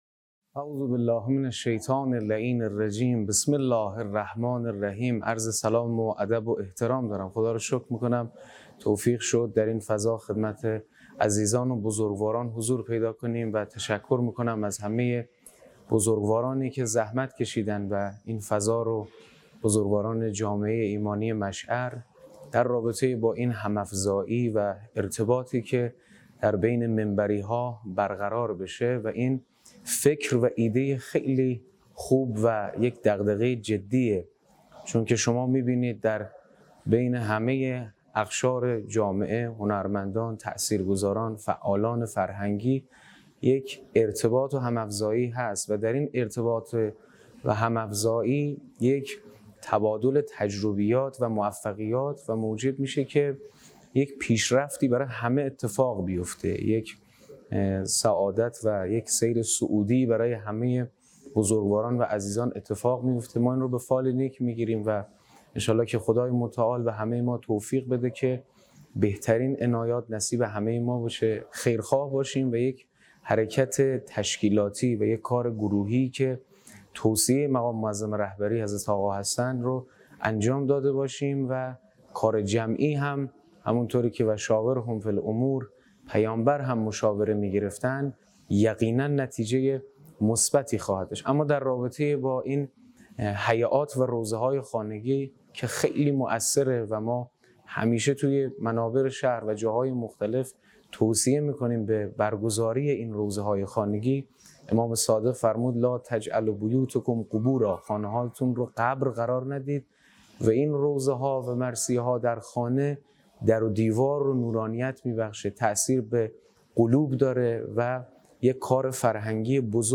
گفتگویی با حجت الاسلام
در اولین نشست صمیمانه جمعی از منبری‌های جوان هیأت‌های کشور